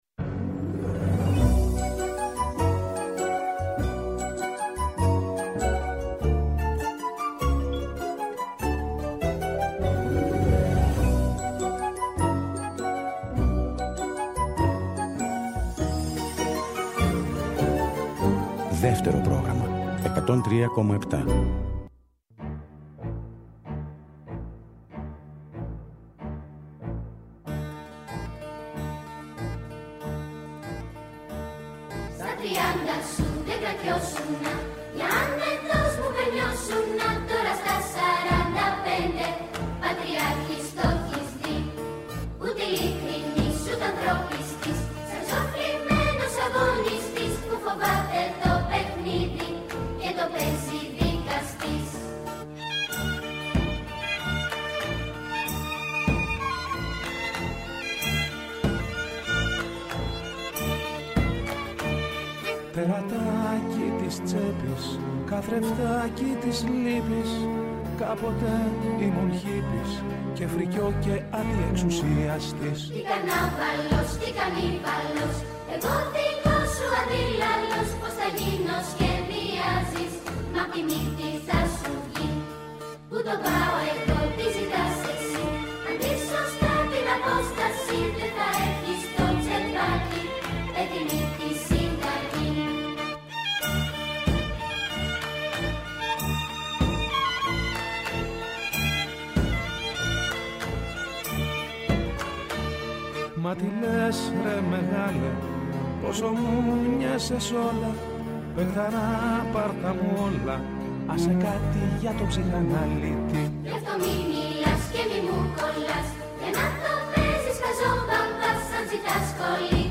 Ο Γιάννης Σπάθας, από το αρχείο της εκπομπής, μιλά για την συζήτηση περί ελληνικού και ξένου στίχου στα τραγούδια των Socrates.